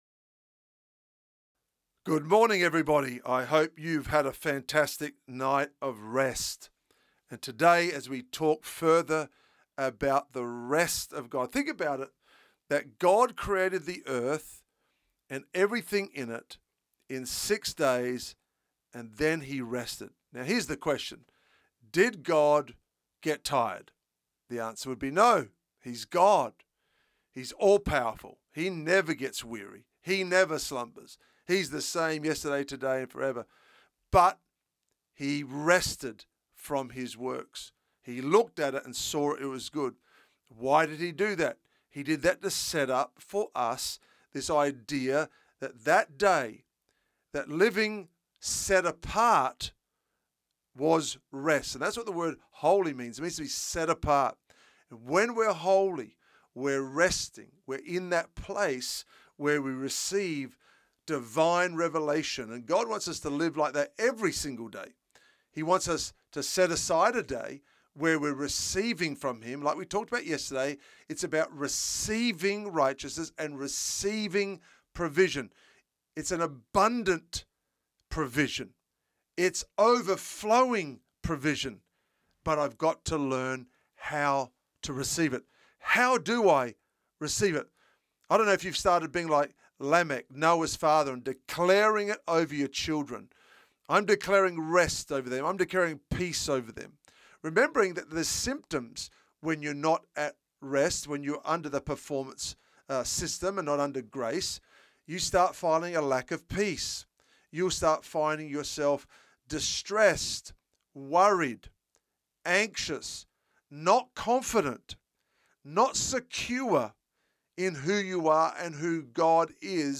daily devotionals on grace